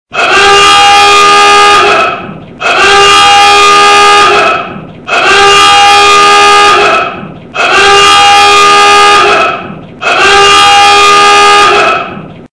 Звуки корабля, теплохода
Шум корабельного ревуна